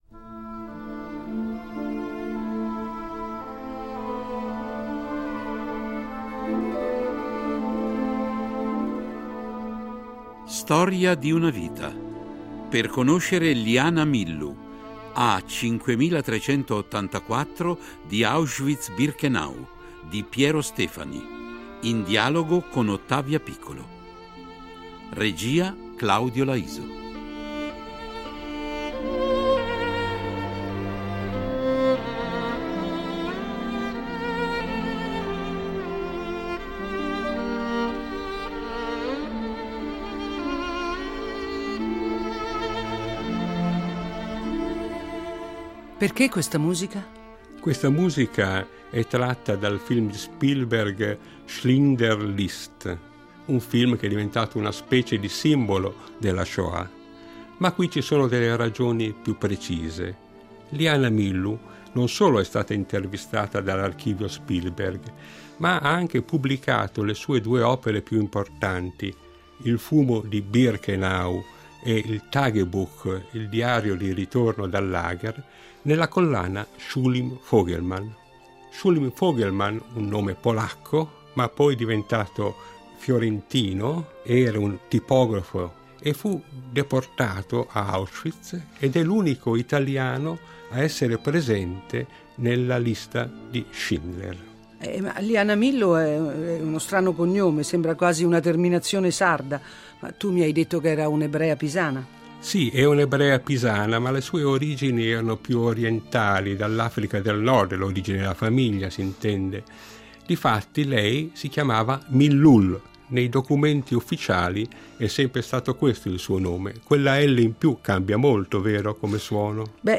in dialogo con Ottavia Piccolo